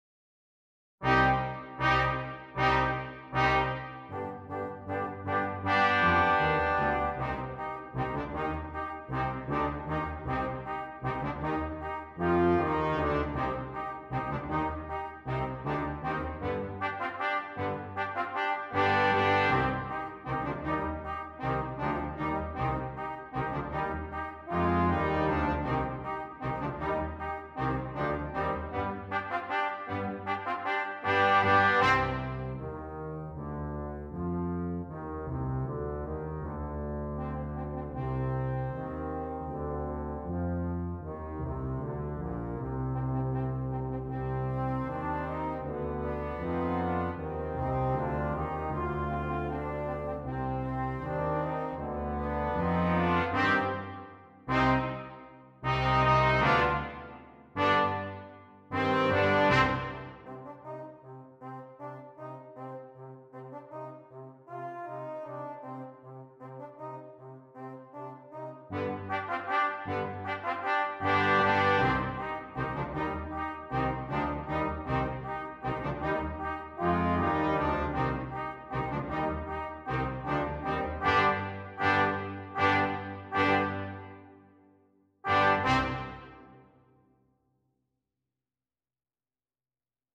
Brass Quintet
features the trombone and tuba